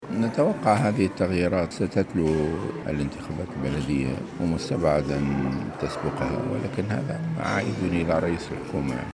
وأضاف الغنوشي، خلال زيارة إلى ولاية المهدية اليوم الأحد في تصريح لمراسل الجوهرة اف ام، أن قرار التحوير الوزاري من عدمه يعود إلى رئيس الحكومة بالتشاور مع جميع المشاركين.